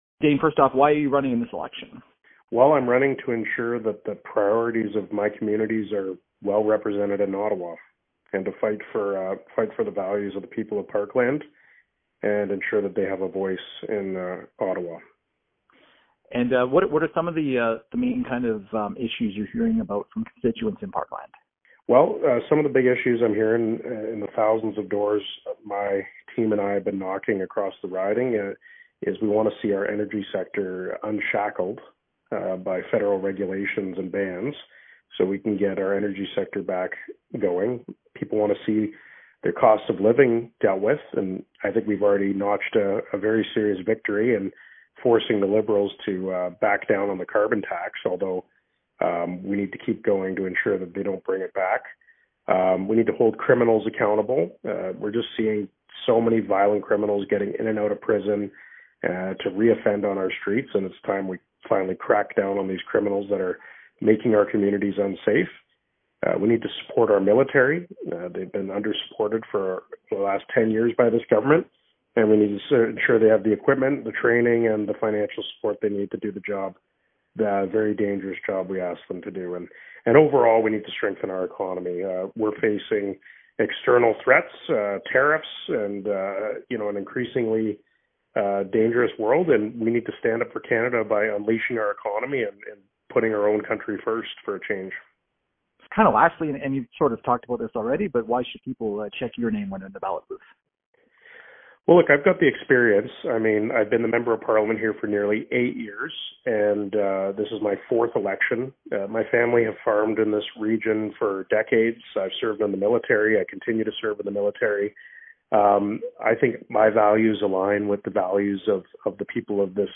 AB A conversation with Conservative candidate Dane Lloyd Listen to this audio